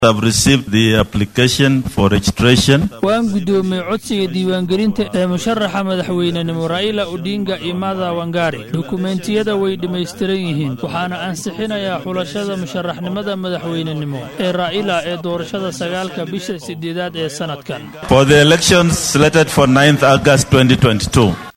Guddoomiyaha guddiga IEBC ,Wafula Chebukati ayaa xarunta Bomas ee magaalada Nairobi sidatan uga dhawaaqay ansaxinta Raila iyo Karua.